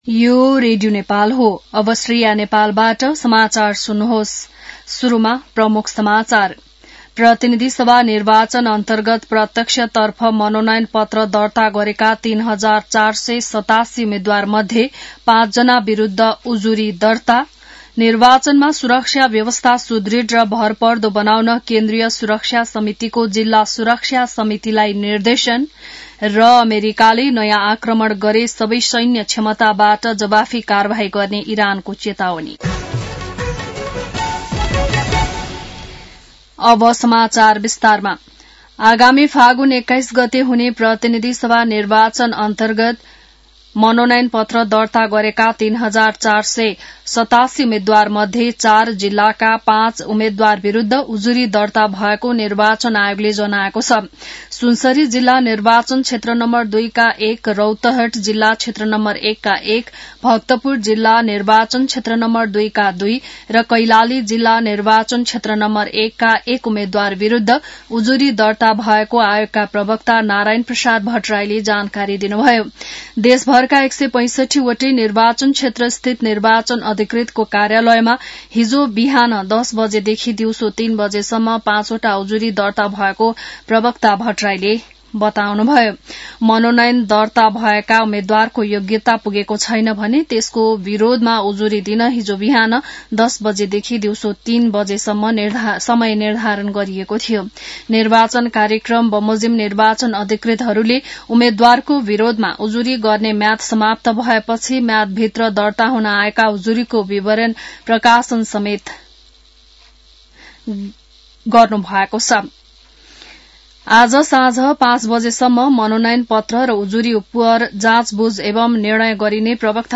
An online outlet of Nepal's national radio broadcaster
बिहान ९ बजेको नेपाली समाचार : ८ माघ , २०८२